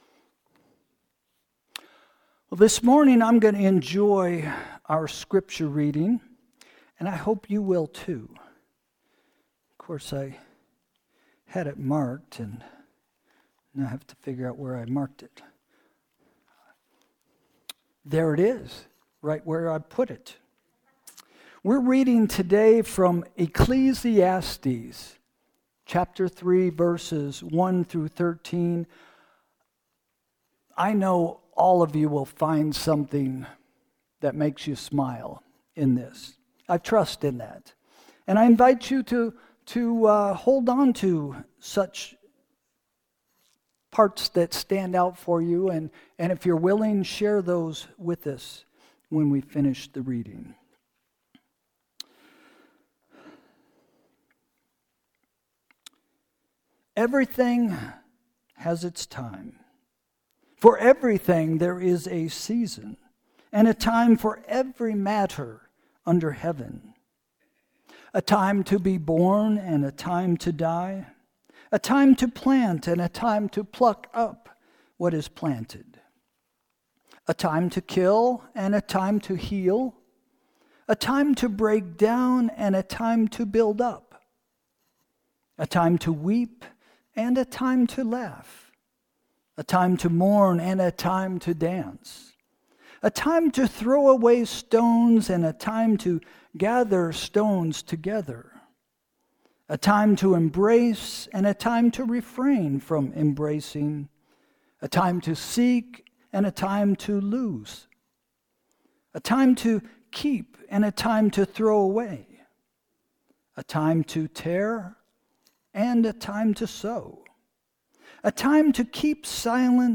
Sermon – January 4, 2026 – “Now Is The Time” – First Christian Church